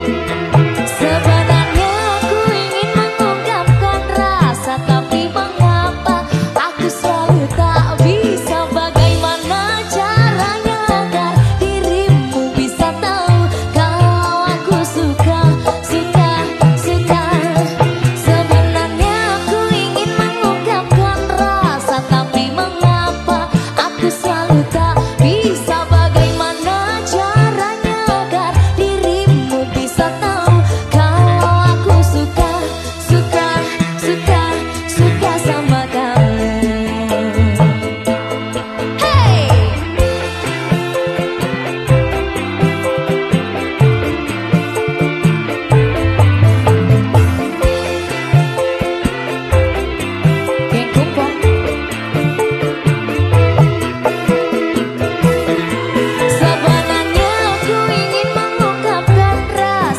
Live
koploan